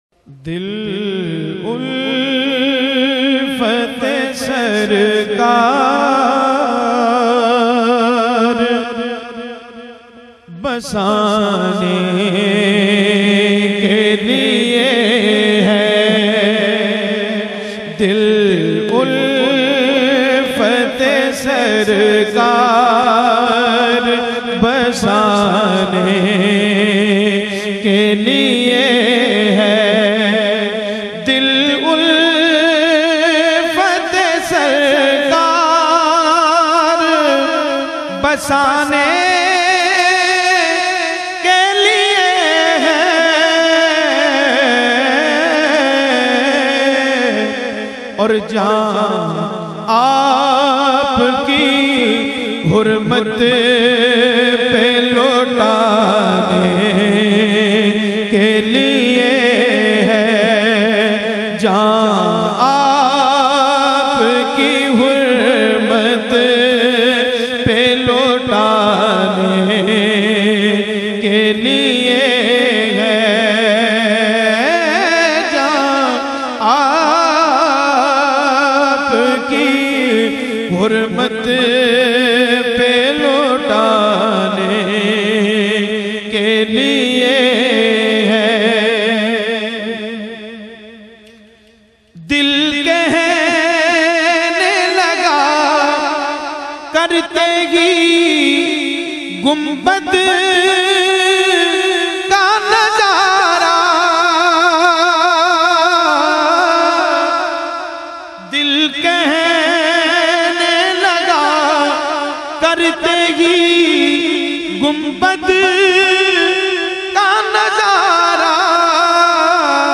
very sweet and magical voice with wonderful control